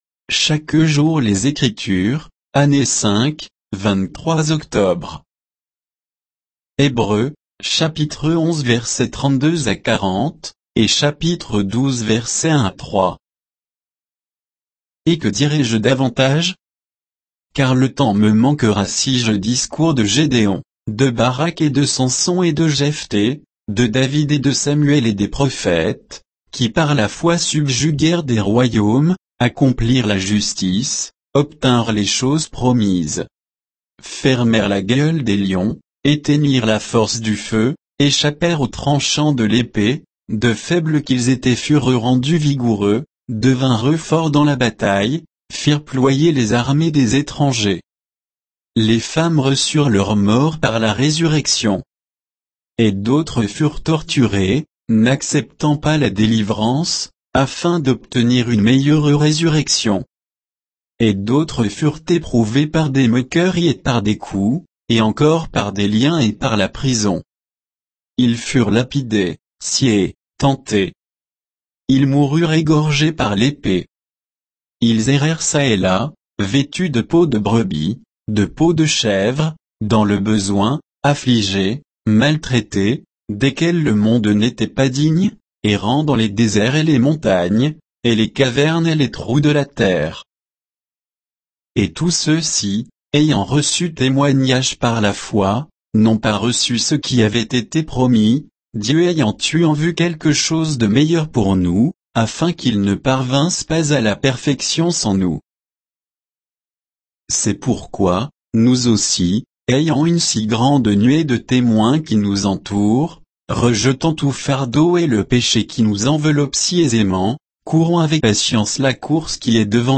Méditation quoditienne de Chaque jour les Écritures sur Hébreux 11